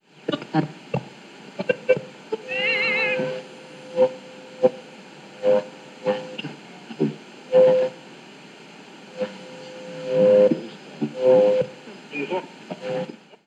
Búsqueda de emisora en una radio 1
Sonidos: Comunicaciones
Receptor de radio